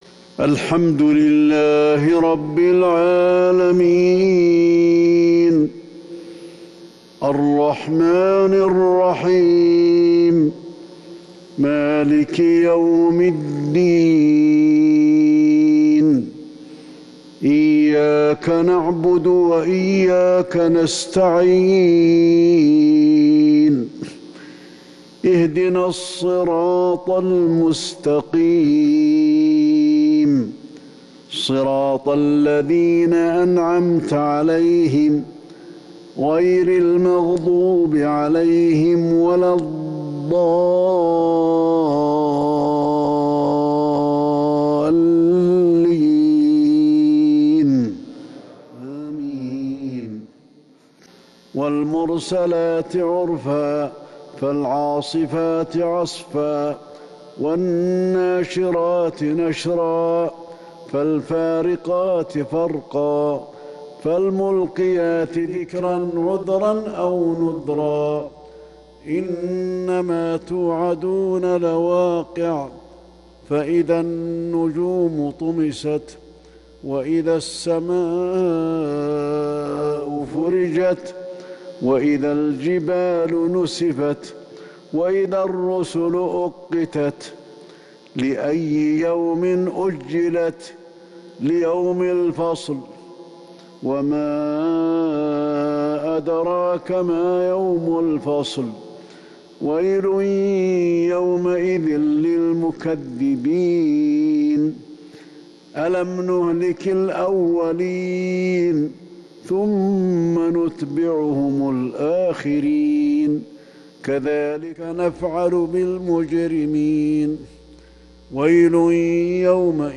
صلاة العشاء 8-3-1442 من سورة المرسلات Isha prayer from Surat Al-Mursalat 10/25/2020 > 1442 🕌 > الفروض - تلاوات الحرمين